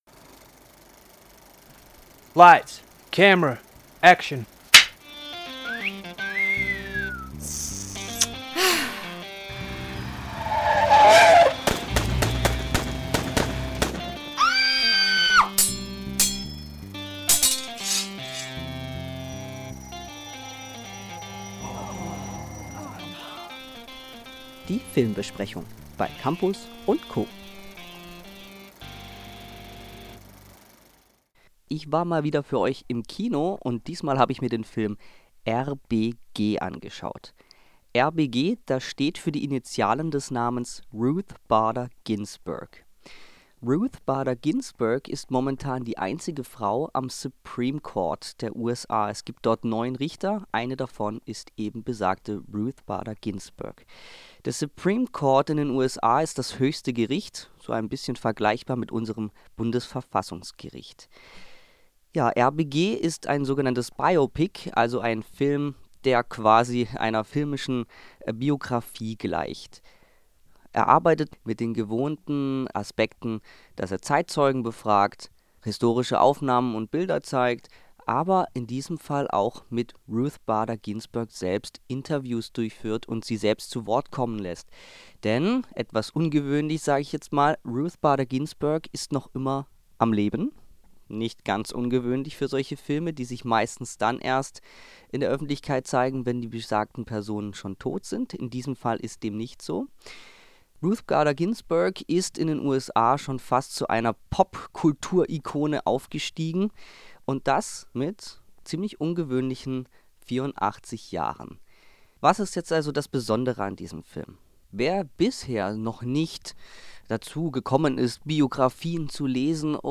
Eine Magazinsendung, in der Hochschulleben, Informationen, Kultur, Unterhaltung, Musik und Spass ihren Platz hatten. Die Moderator:innen waren Studierende an der PH.